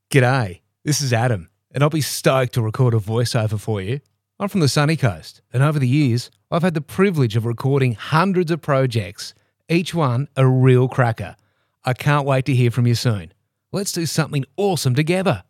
Male
English (Australian)
Adult (30-50)
It balances warmth with authority, ideal for commercial, narration and brand-led work. The tone is natural, trustworthy and clear, well-suited for both high-energy spots and calm, informative reads.
Natural Speak
All our voice actors have professional broadcast quality recording studios.